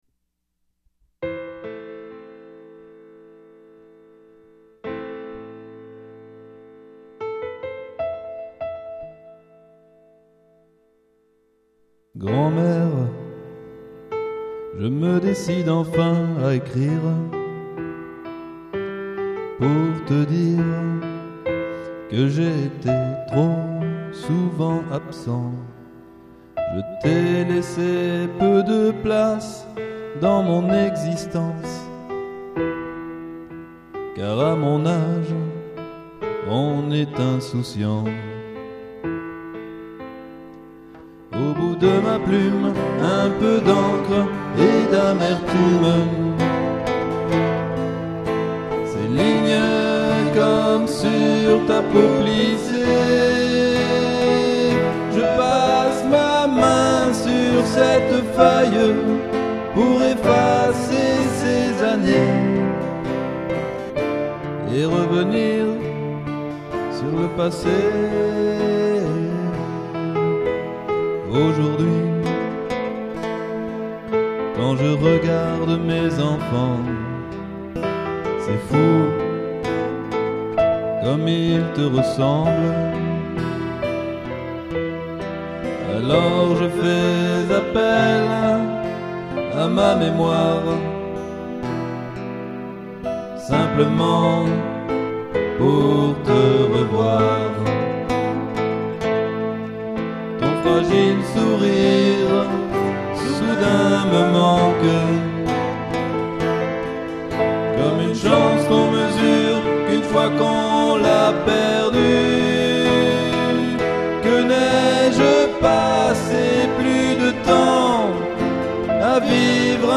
Choeurs
piano